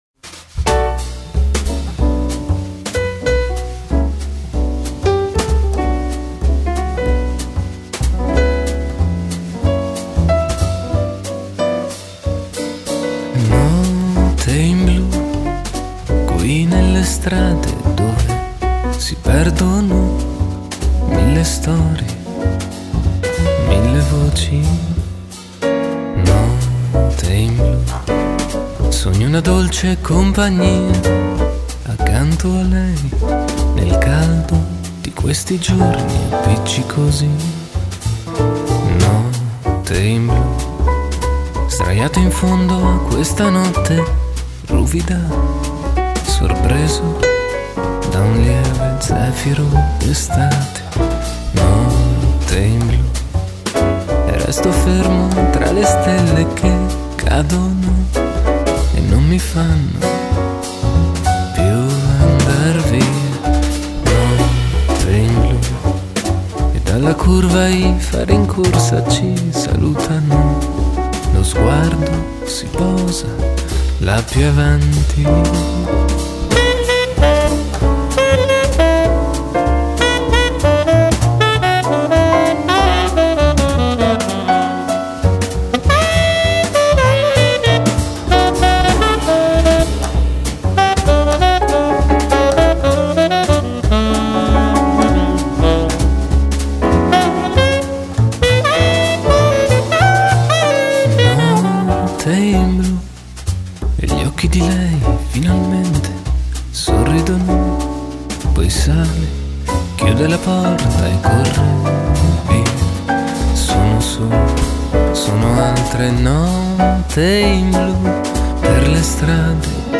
voce e chitarra classica
piano
contrabbasso
fisarmonica
chitarra acustica, classica
fiati
flicorno, tromba
violino
violoncello
Mandolino
Batteria, percussioni
lento e tutto da godere